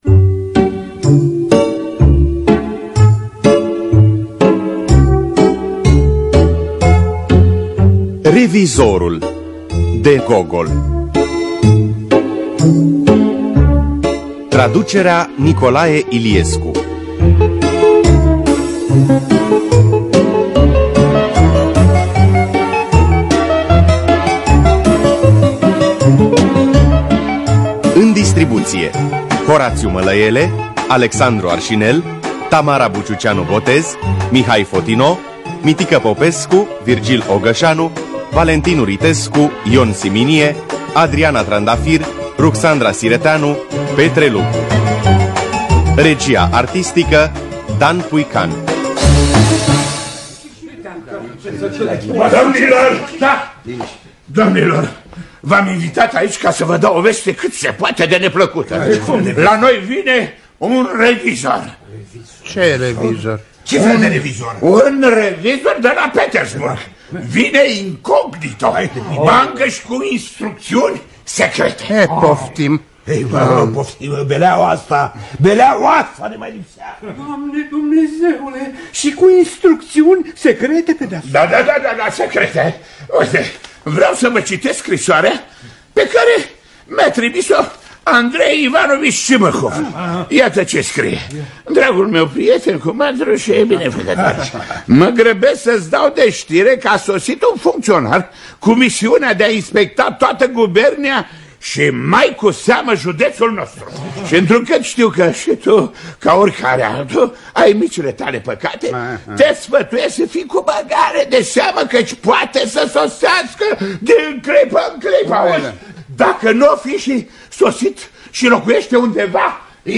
Nikolai Vasilievici Gogol – Revizorul (2005) – Teatru Radiofonic Online